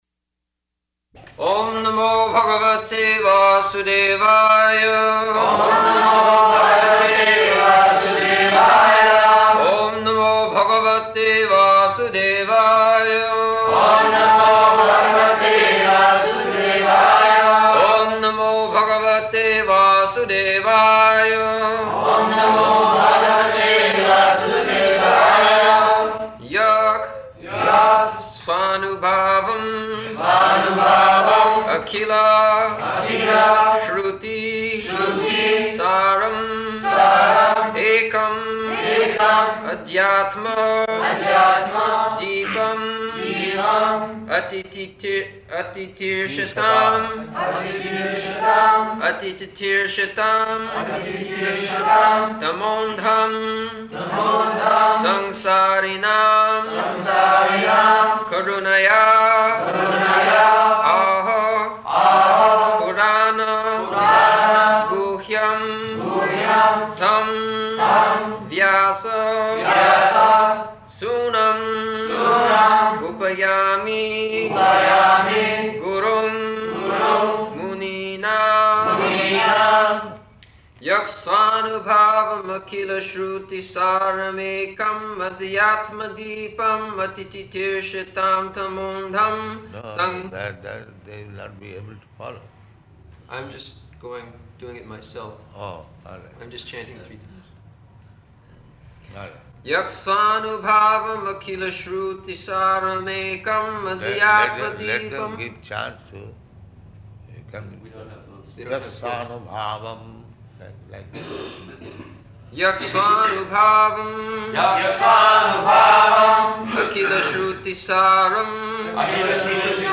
May 27th 1974 Location: Rome Audio file
[devotees repeat]